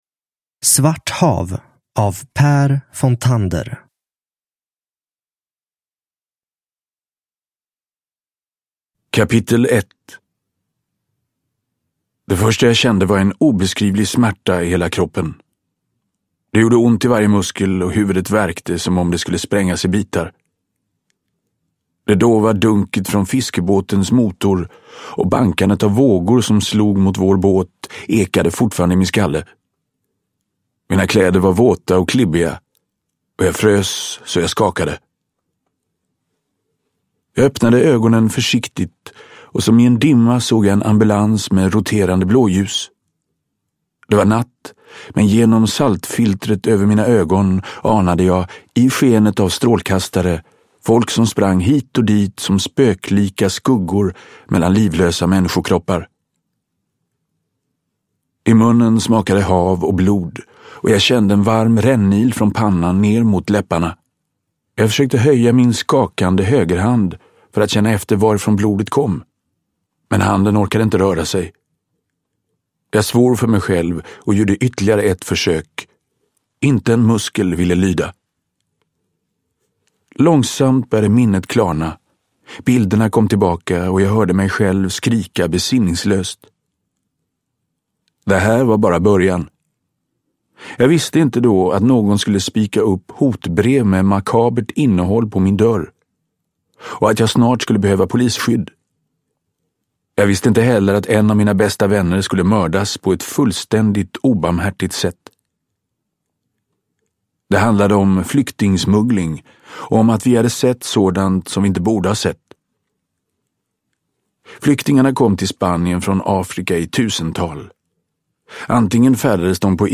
Svart hav – Ljudbok – Laddas ner
Uppläsare: Magnus Roosmann